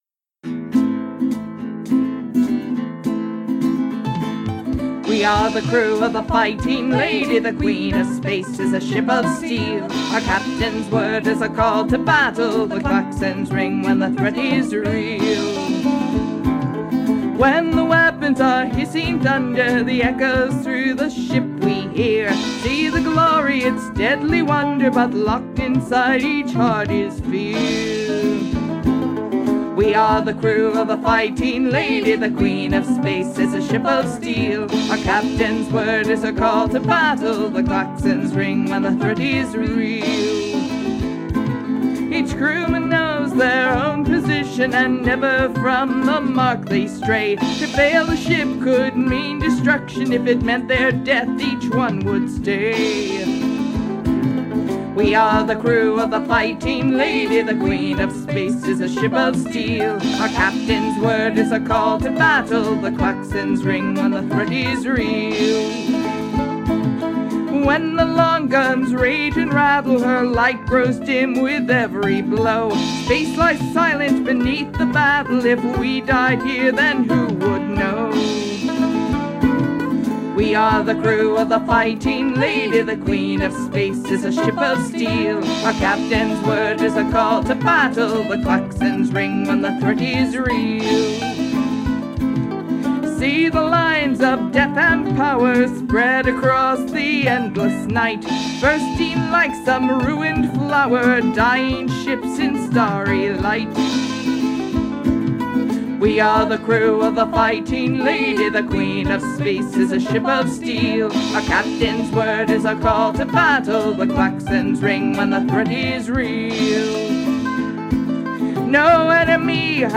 которая занималась публикацией музыки в жанре "филк"